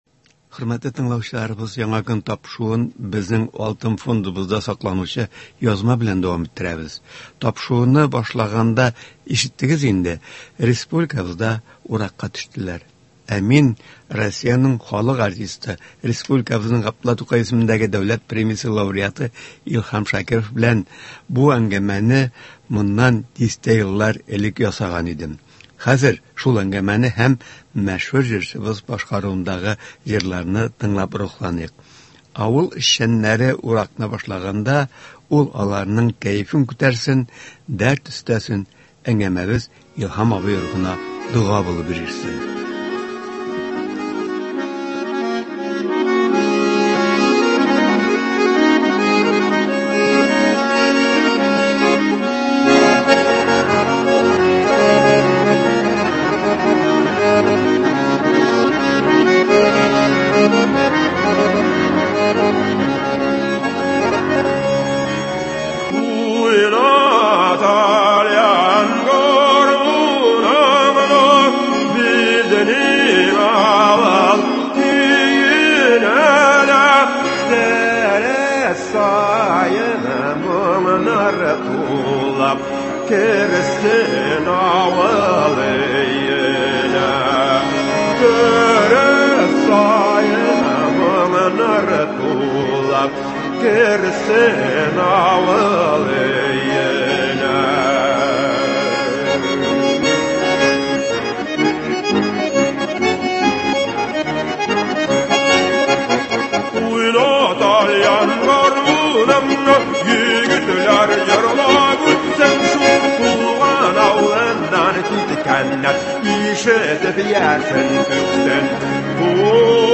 Бүгенге тапшыруда радионың Алтын фондында сакланучы язманы ишетә аласыз. Игътибарыгызга Россиянең халык артисты, Г. Тукай исемендәге дәүләт премиясе лауреаты Илһам Шакиров белән әңгәмәне тәкъдим итәбез .